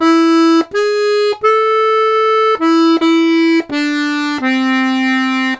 Index of /90_sSampleCDs/USB Soundscan vol.40 - Complete Accordions [AKAI] 1CD/Partition A/01-80ADONOL